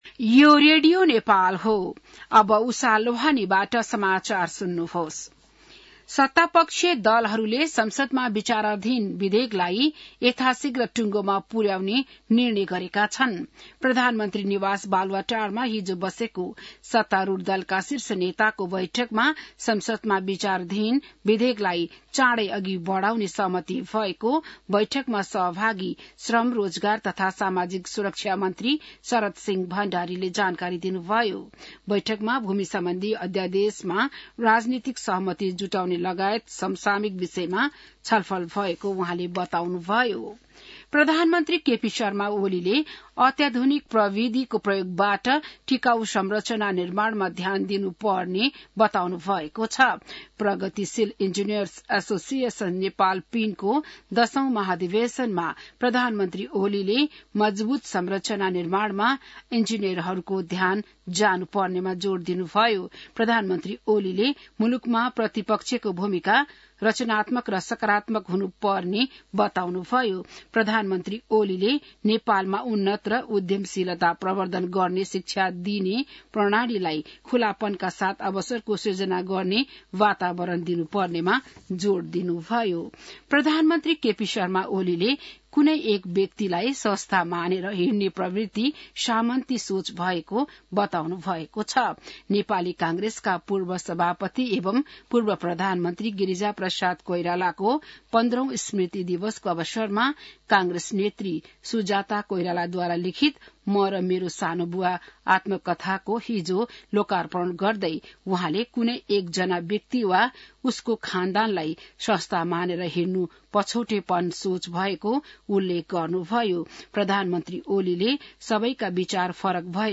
बिहान १० बजेको नेपाली समाचार : १३ चैत , २०८१